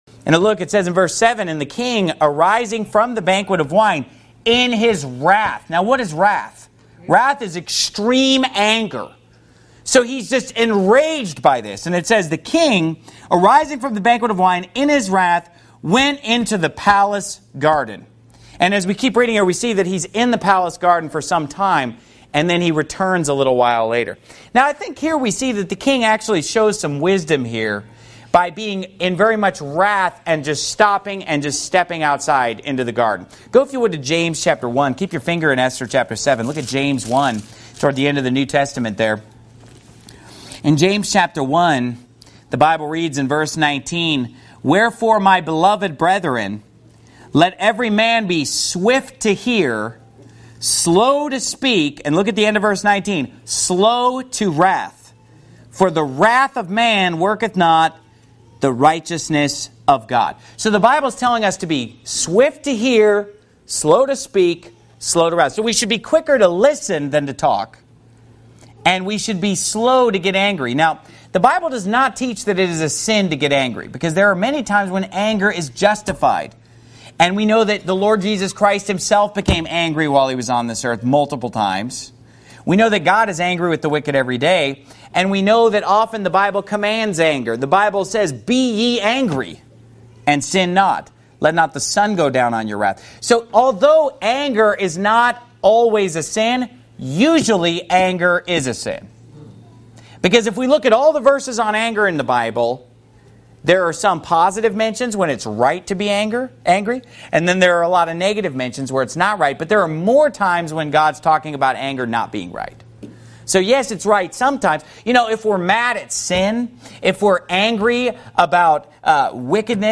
Sermon Clips Pastor Steven Anderson